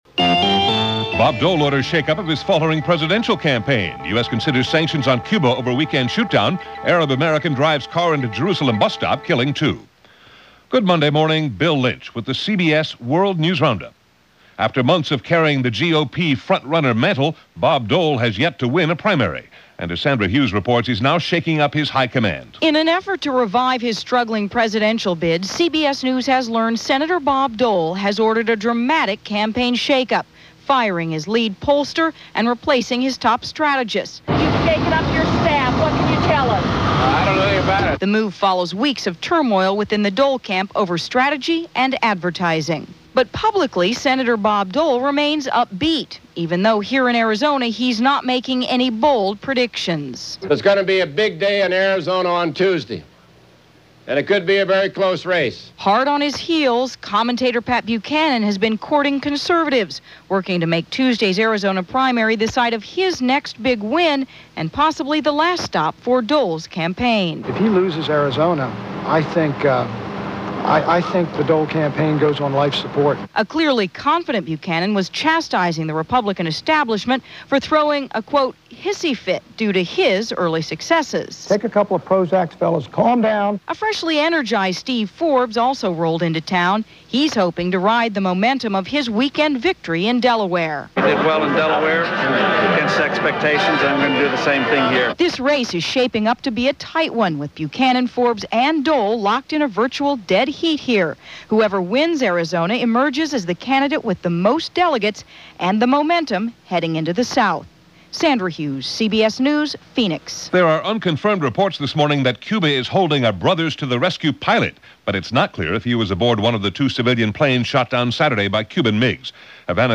[laterpay_premium_download target_post_id=”7147″ heading_text=”Download For $1.99:” description_text=”February 26, 1996 – CBS World News Roundup – Gordon Skene Sound Collection” content_type=”link”]
And so it went for this day in 1996 – as presented by The CBS World News Roundup for Monday, February 26, 1996.